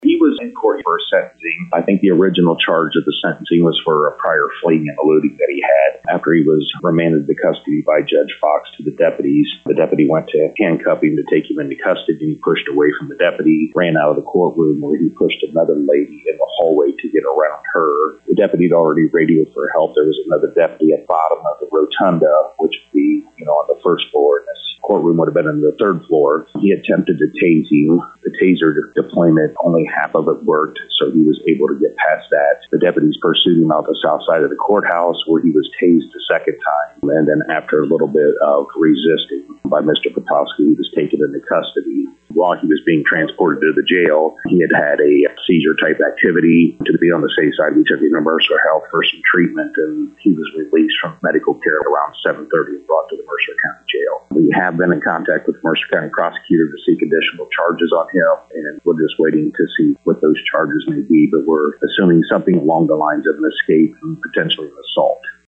To hear comments with Mercer County Sheriff Doug Timmerman: